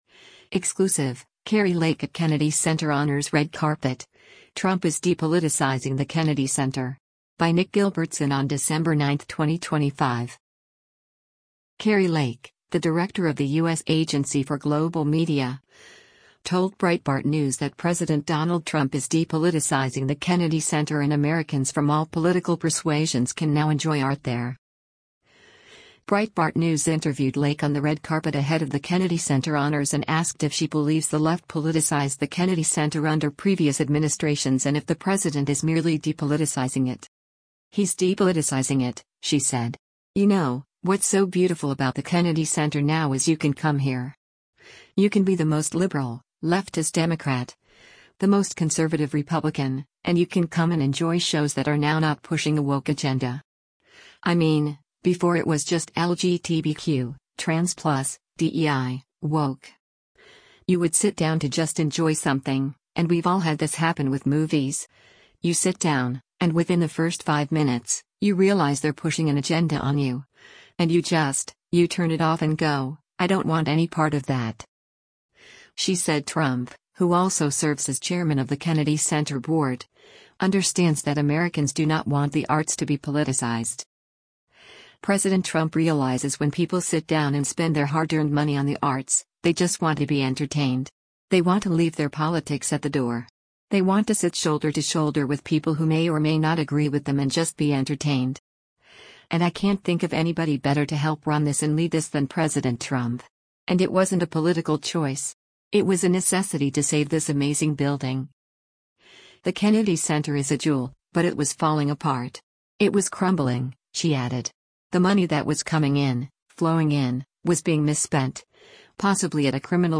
Breitbart News interviewed Lake on the Red Carpet ahead of the Kennedy Center Honors and asked if she believes the left politicized the Kennedy Center under previous administrations and if the president is merely depoliticizing it.